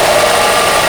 fenestron_ec135.wav